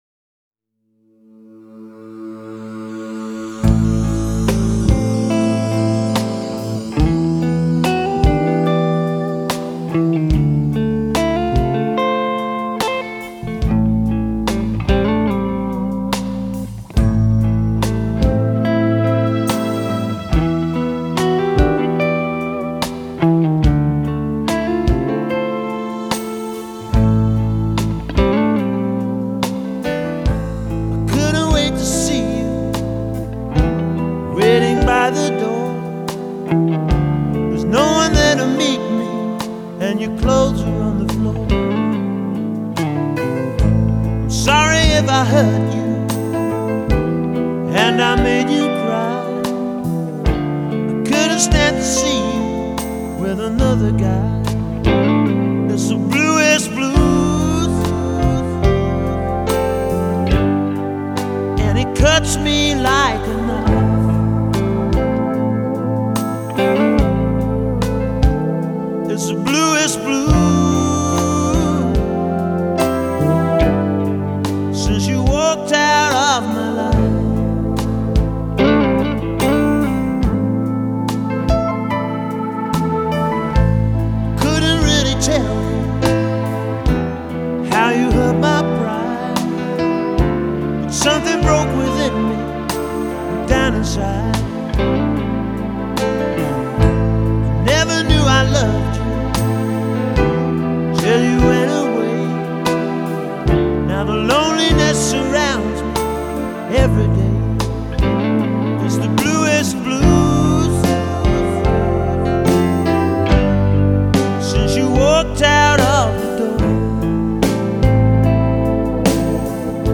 Blues Rock